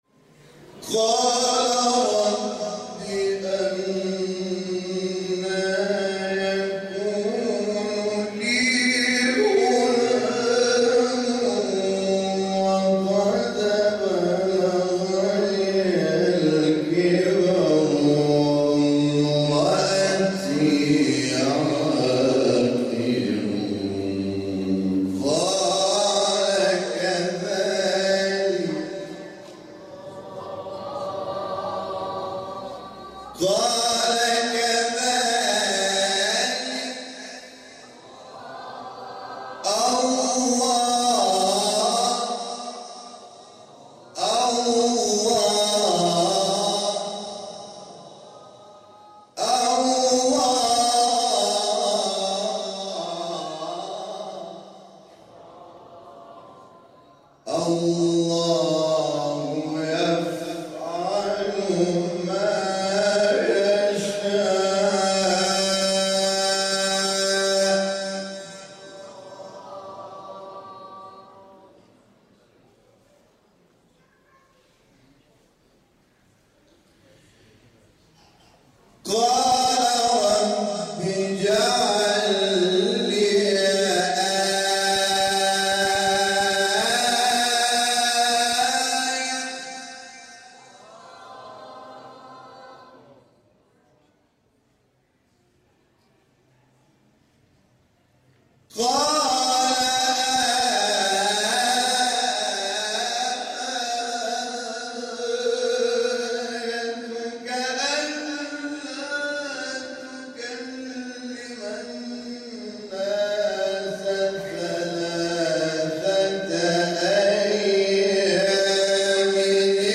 مقطع حجاز استاد حامد شاکرنژاد | نغمات قرآن | دانلود تلاوت قرآن